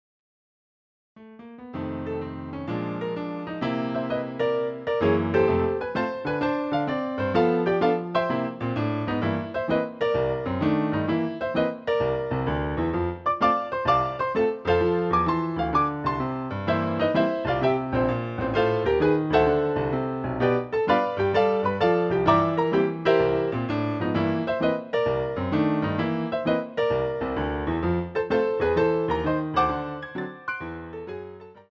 CD quality digital audio Mp3 file
using the stereo sampled sound of a Yamaha Grand Piano.